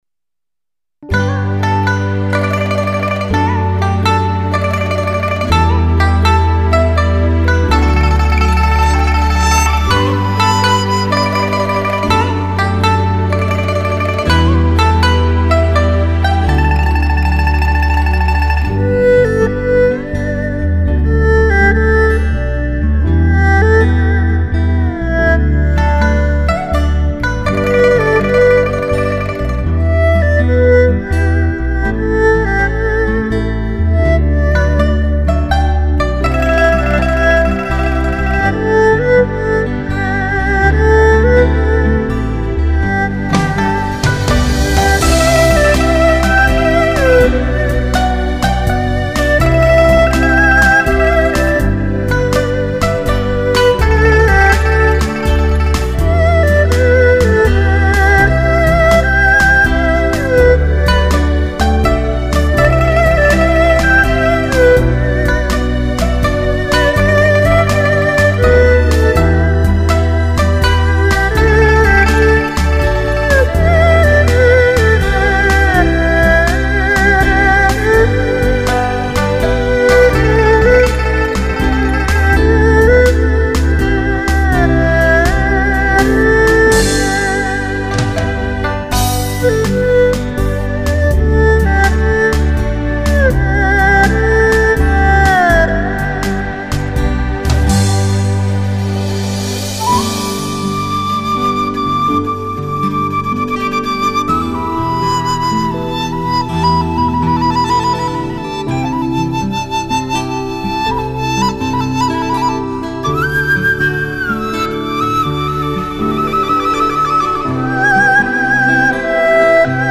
MIDI